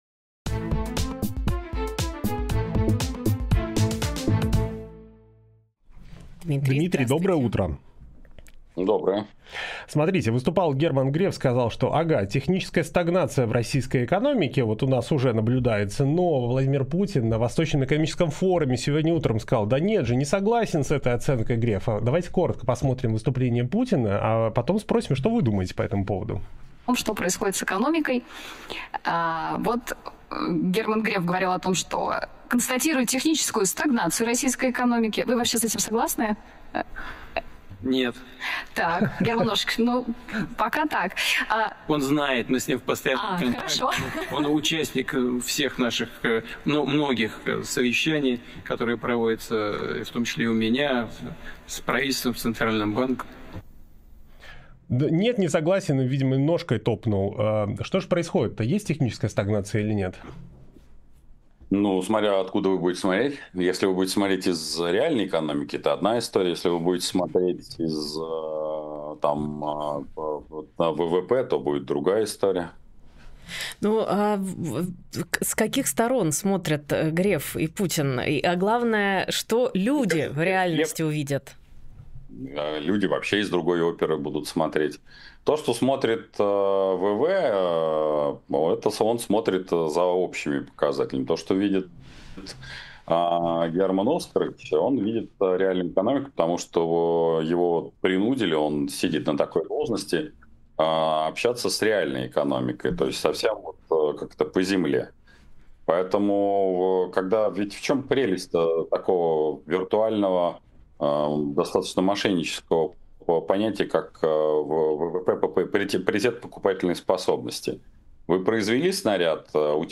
Фрагмент эфира от 5 сентября